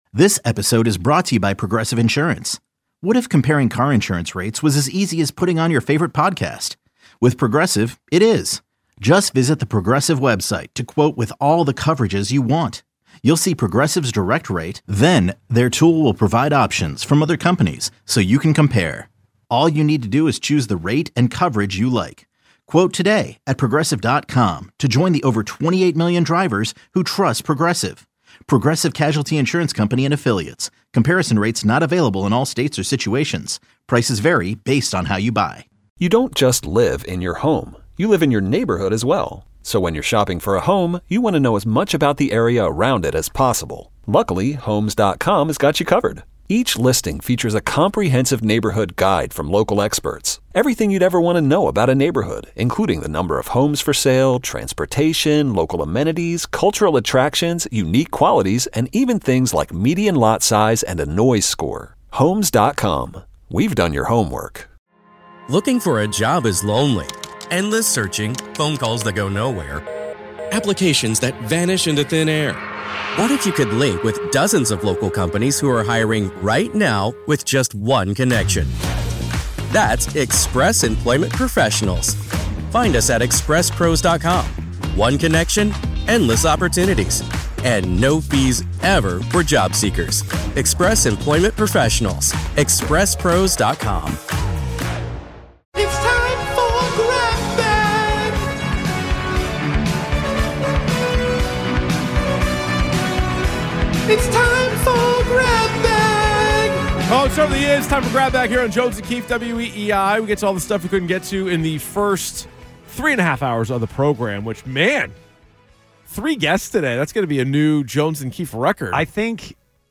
airs live weekdays 10 a.m. - 2 p.m. on WEEI-FM